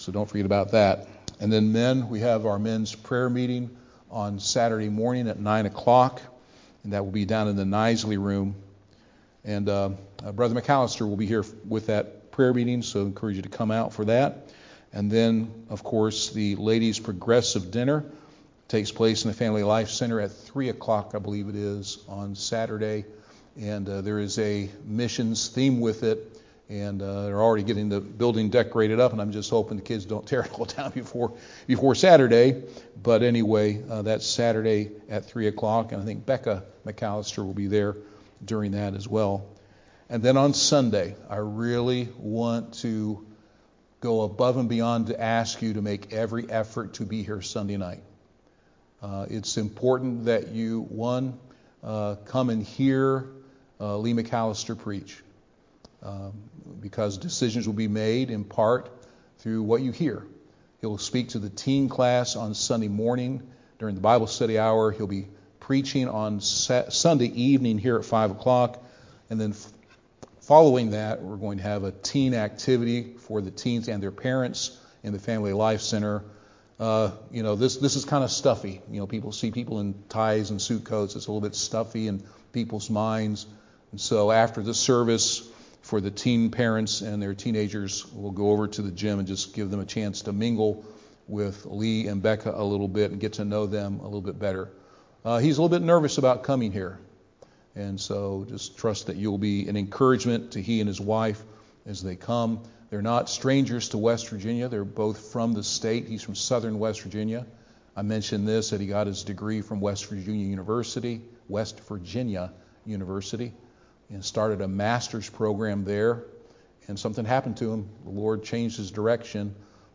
Midweek Prayer Meeting, 03/04/2026